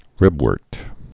(rĭbwûrt, -wôrt)